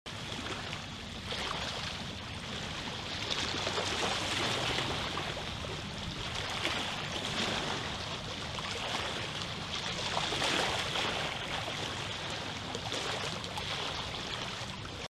Gentle ocean waves crashing on sound effects free download
Gentle ocean waves crashing on the rocks creating a beautiful soundscape.